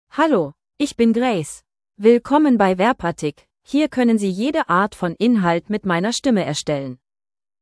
FemaleGerman (Germany)
GraceFemale German AI voice
Grace is a female AI voice for German (Germany).
Voice sample
Listen to Grace's female German voice.
Grace delivers clear pronunciation with authentic Germany German intonation, making your content sound professionally produced.